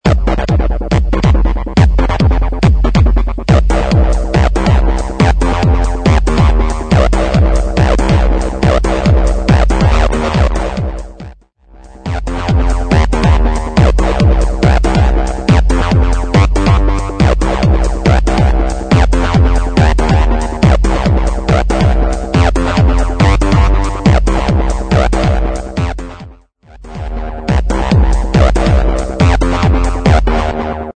140 BPM
Electronic